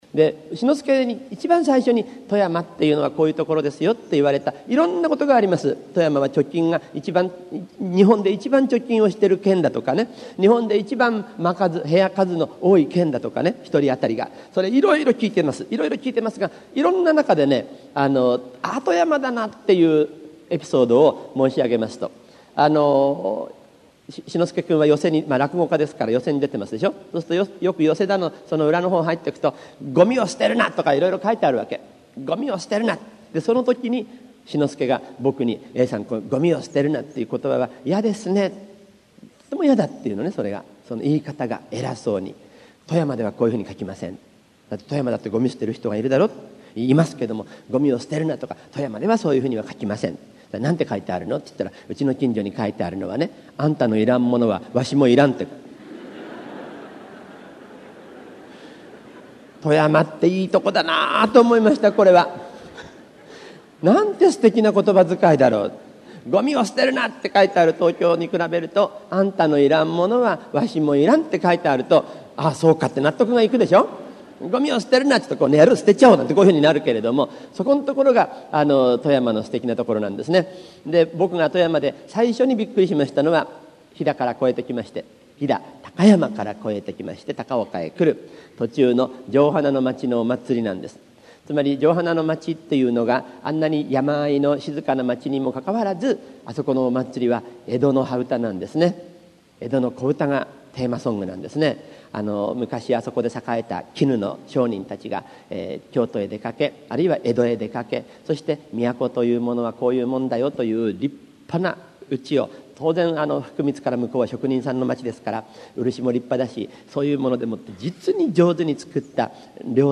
名だたる文筆家が登場する、文藝春秋の文化講演会。
（1988年11月13日 富山県高岡市民会館 菊池寛生誕百周年記念講演会より）